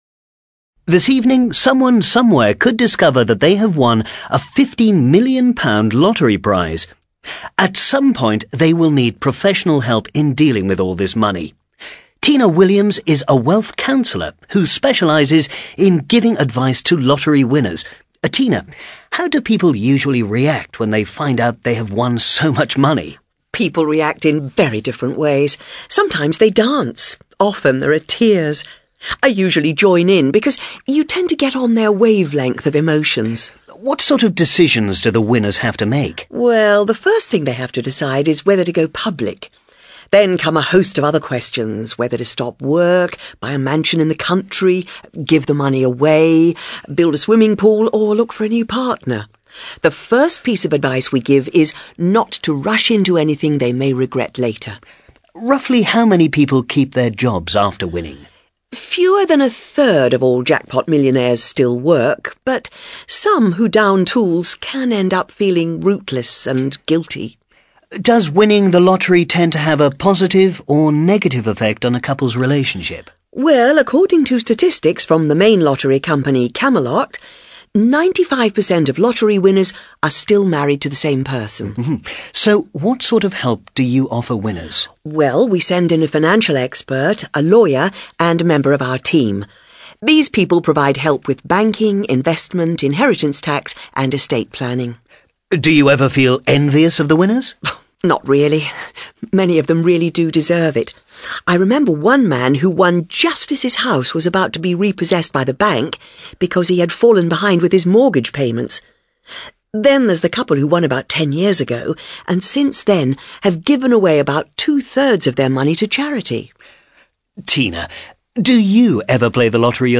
A TV interview